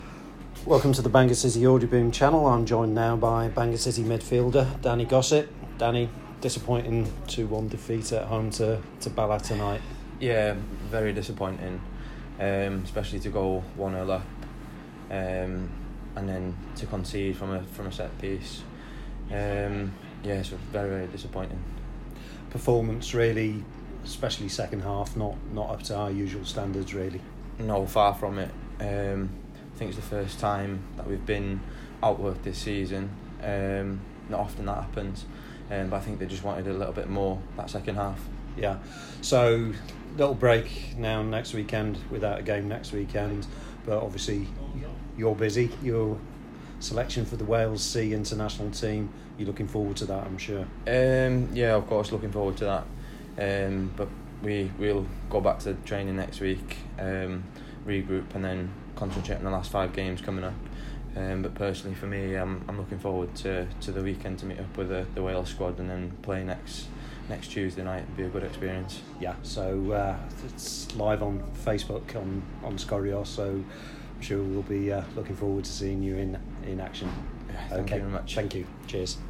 Citizens Interview